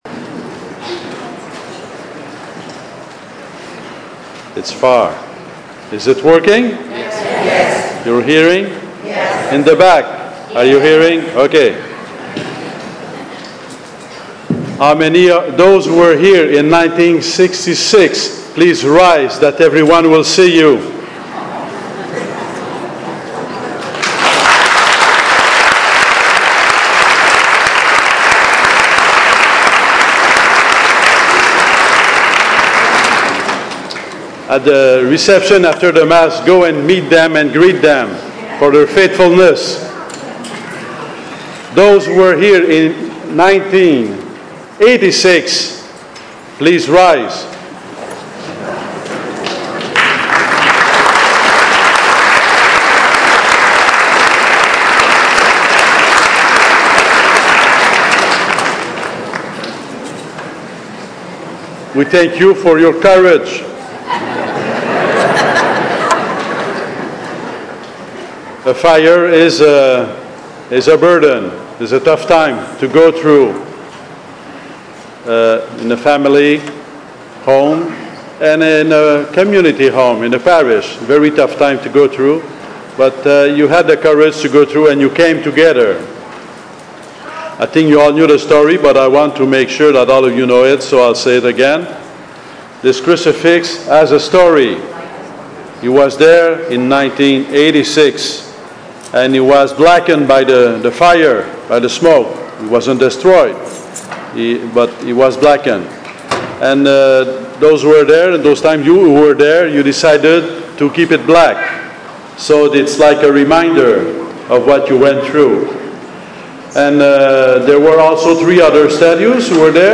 Becket50thMass_homily.mp3